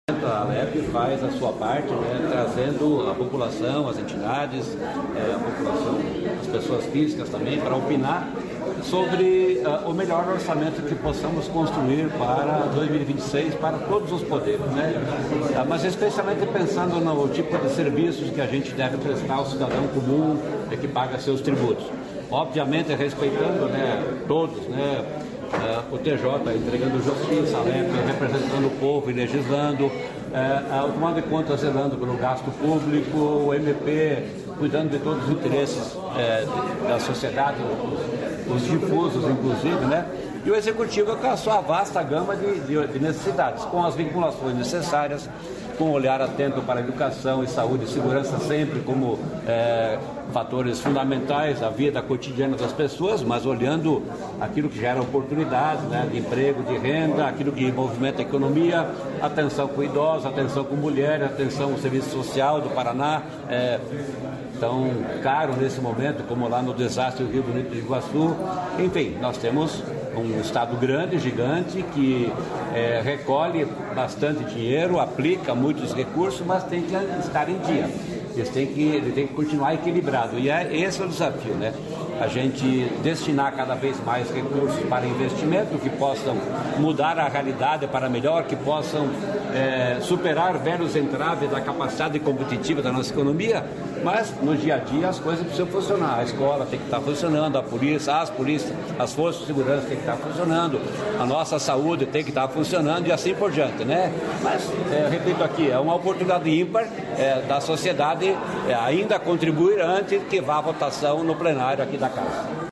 Sonora do secretário da Fazenda, Norberto Ortigara, sobre orçamento de R$ 81,6 bilhões para 2026
NORBERTO ORTIGARA - AUDIENCIA ALEP.mp3